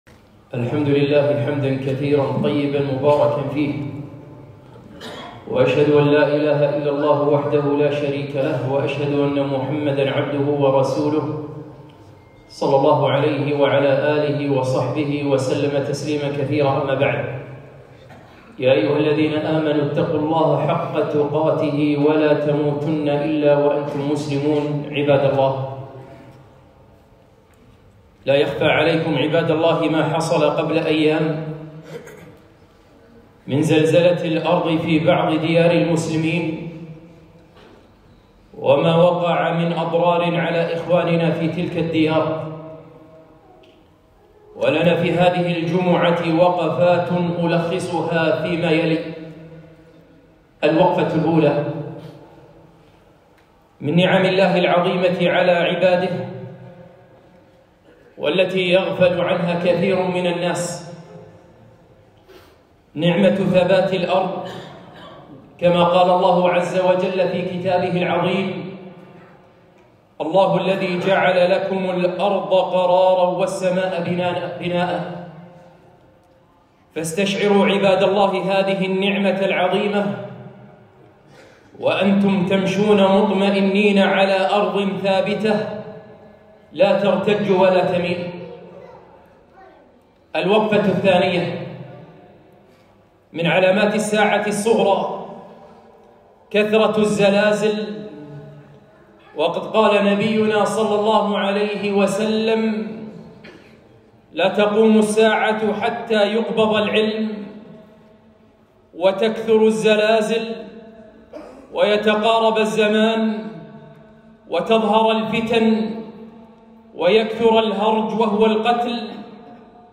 خطبة - وقفات مع حادثة زلزال سوريا وتركيا